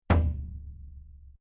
Bass.mp3